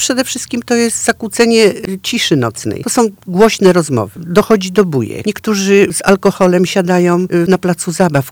– mówi Krystyna Smolarek, stargardzka radna i mieszkanka osiedla.